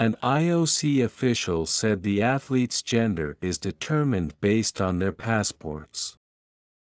１文ずつ区切ったスロー音声を再生し、文字を見ずにリピートしましょう。